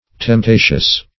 Temptatious \Temp*ta"tious\